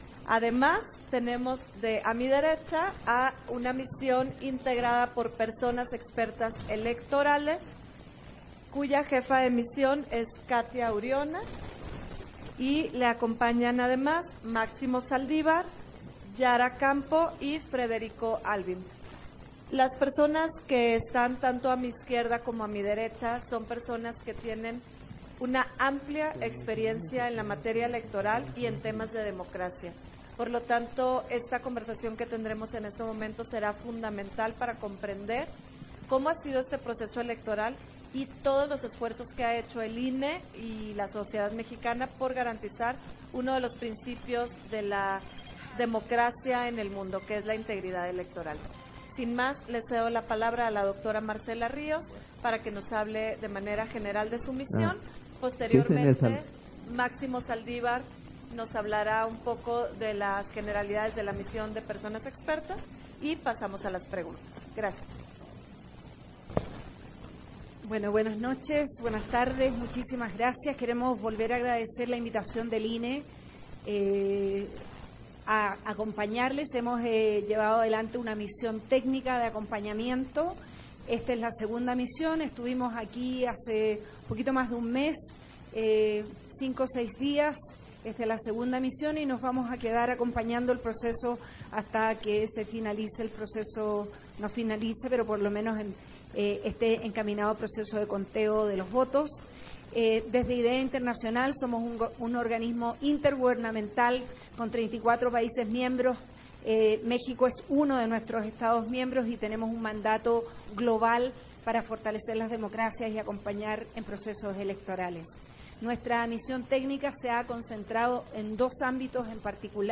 Versión estenográfica de la conferencia de prensa ofrecida por Misiones Extranjeras, durante la jornada electoral de la elección del Poder Judicial